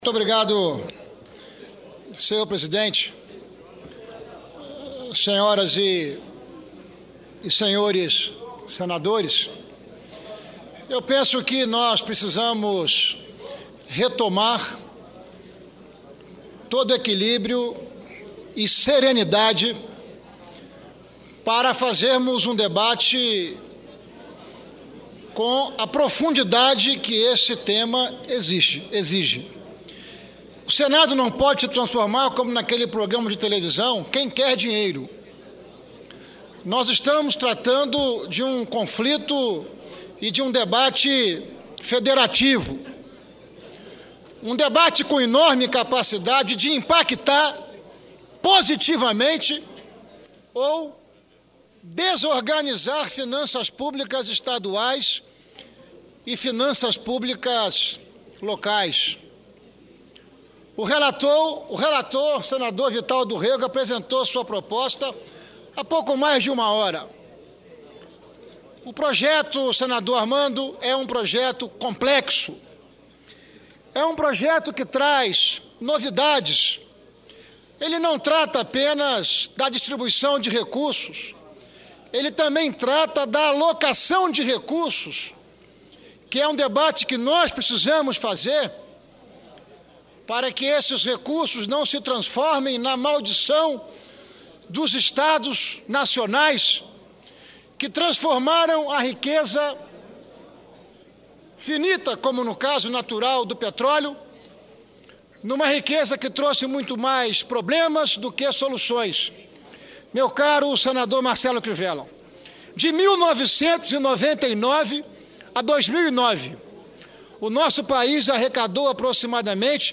Pronunciamento do senador Ricardo Ferraço